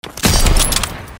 Play Headshot Sounds - SoundBoardGuy
Play, download and share headshot sounds original sound button!!!!
headshot_KdA2zdn.mp3